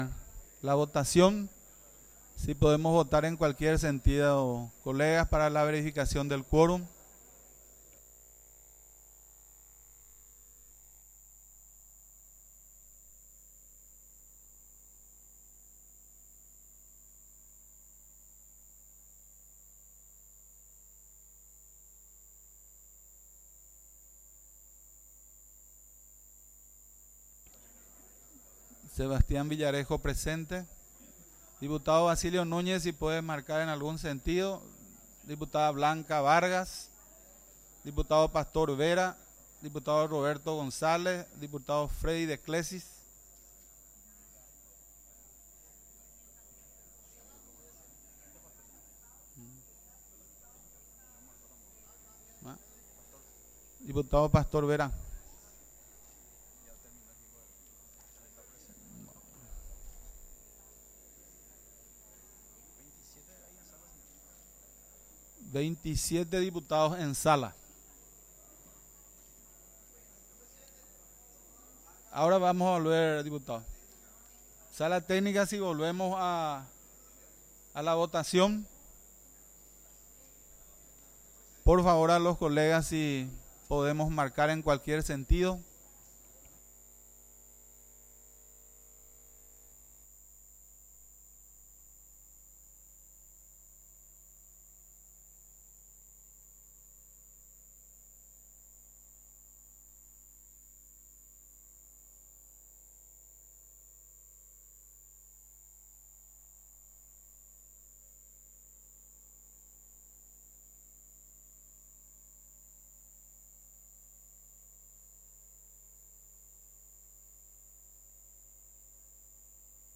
Sesión Ordinaria, 28 de junio de 2023